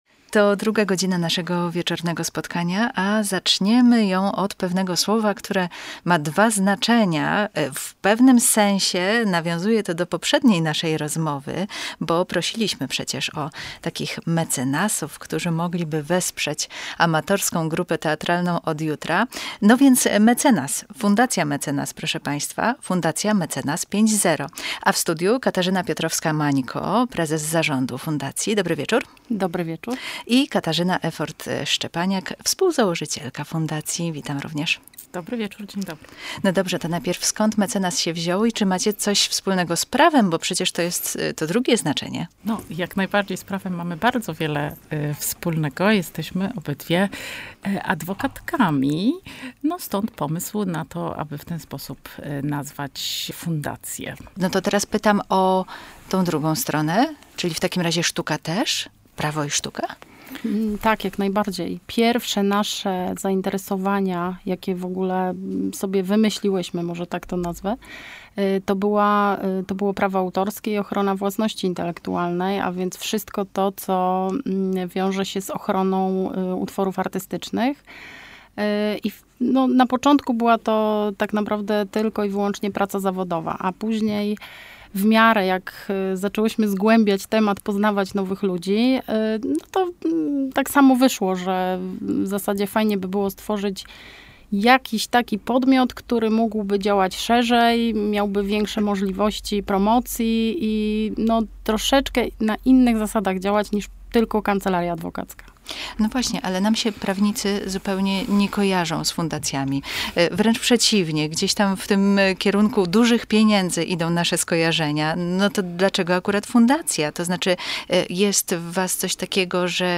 Zachęcamy do wysłuchania rozmowy wyemitowanej w programie Radio Łódź na wieczór.